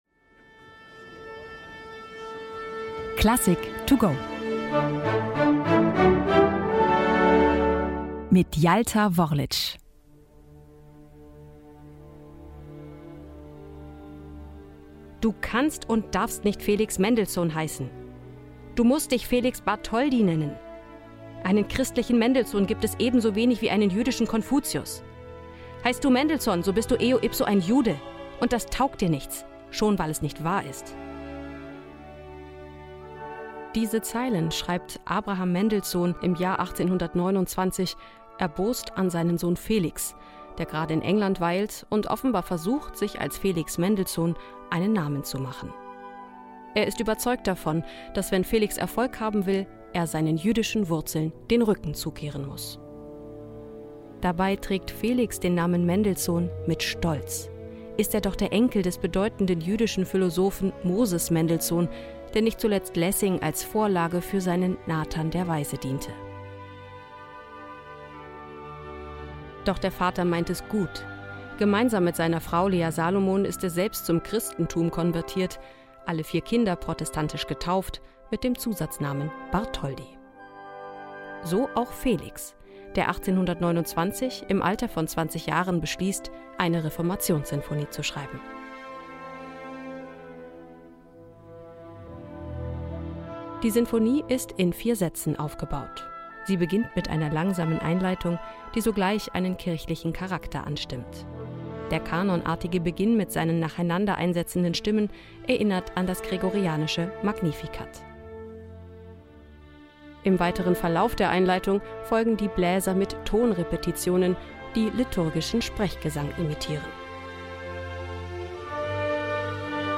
Werkeinführung "to go".